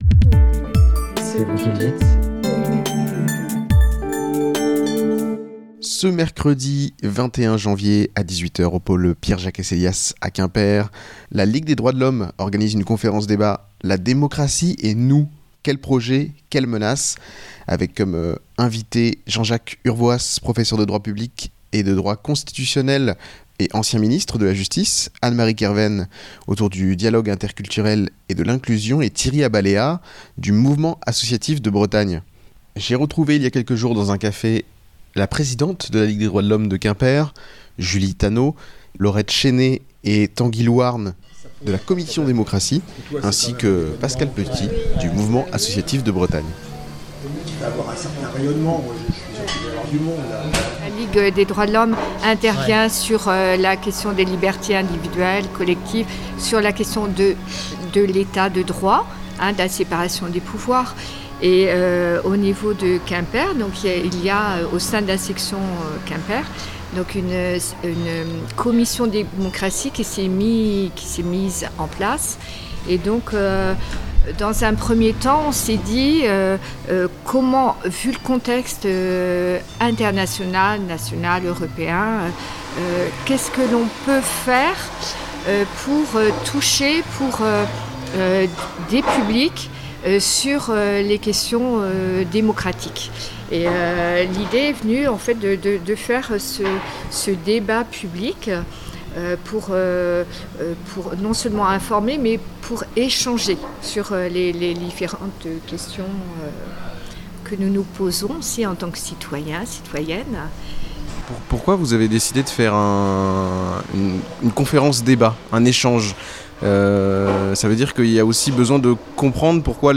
Il y a quelques jours, Radio U a rencontré dans un café,